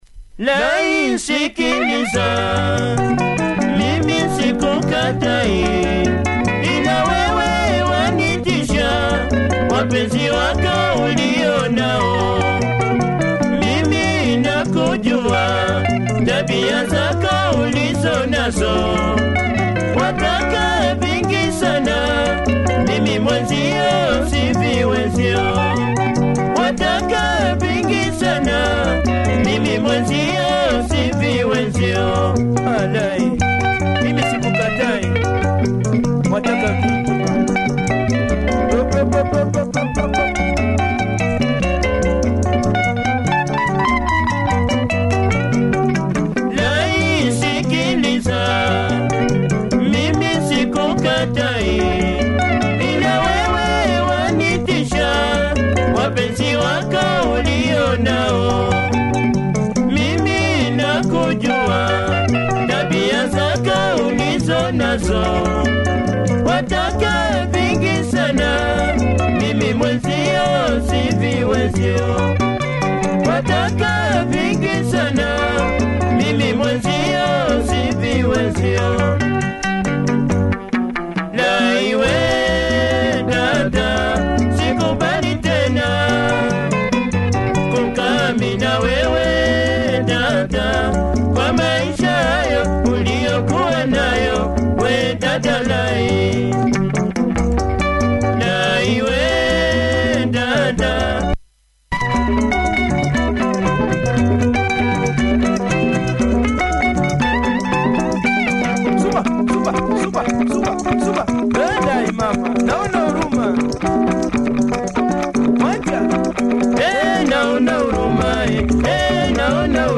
Great melody on this one, nice breakdown.